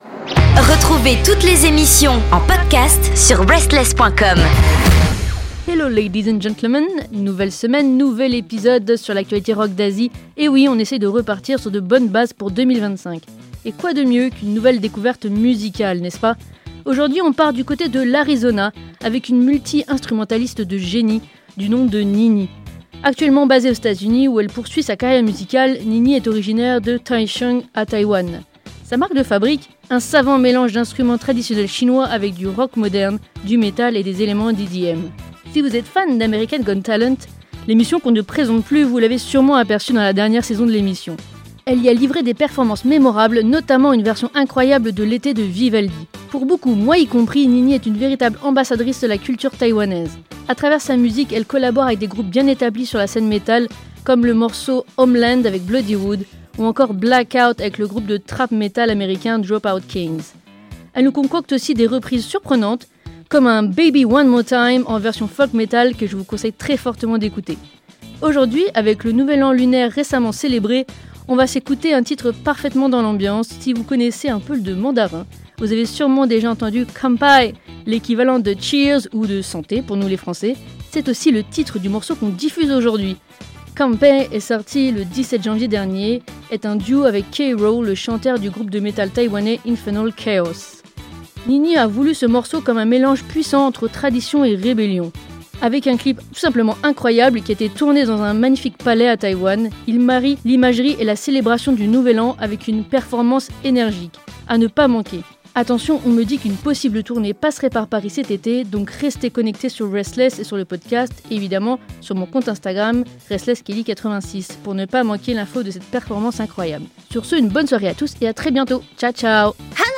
RSTLSS vous souhaite une bonne nouvelle année lunaire en beauté avec une artiste d'un talent incroyable qui mélange les musiques traditionnelles avec l'énergie du métal !